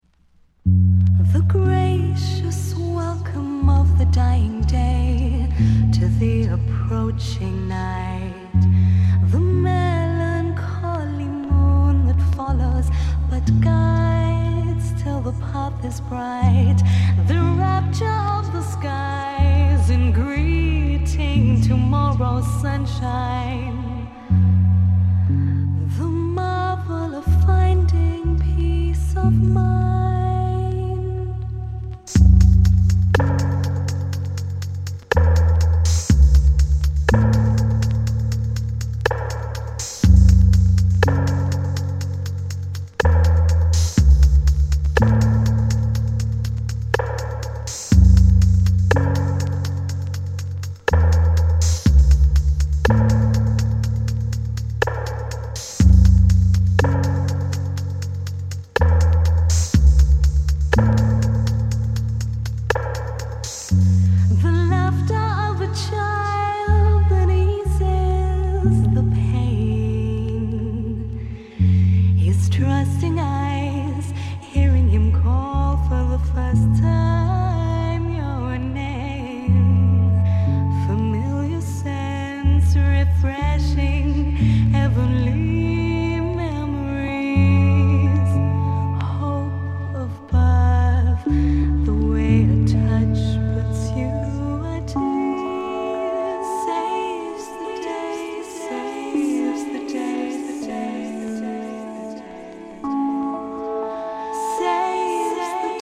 クラブ、ダンス